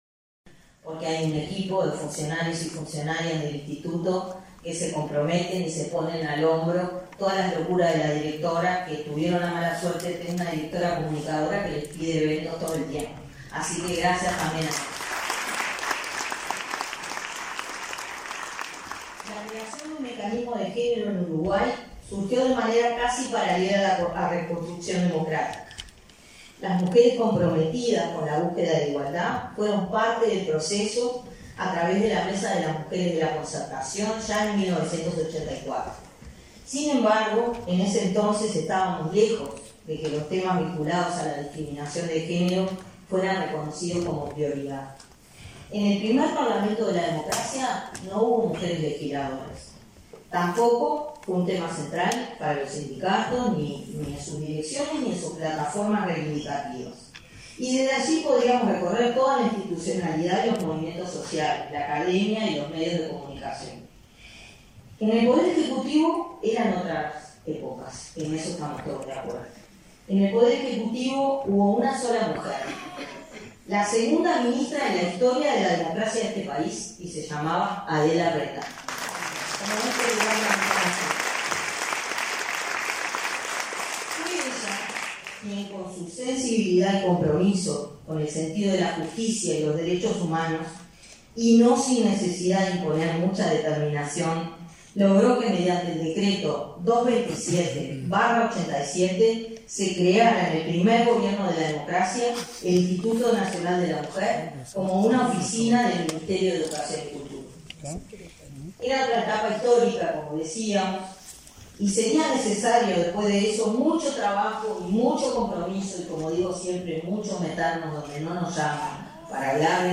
Palabras de la directora del Inmujeres, Mónica Bottero
Palabras de la directora del Inmujeres, Mónica Bottero 22/08/2022 Compartir Facebook X Copiar enlace WhatsApp LinkedIn El Instituto Nacional de las Mujeres (Inmujeres) celebró 35 años de existencia. En el evento, realizado el 22 de agosto, participó la directora del organismo, Mónica Bottero.